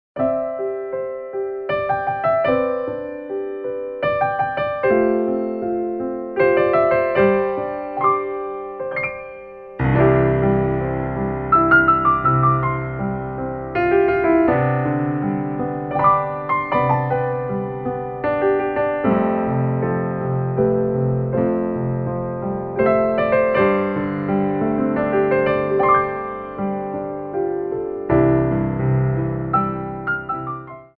Ports de Bras 1
3/4 (8x8)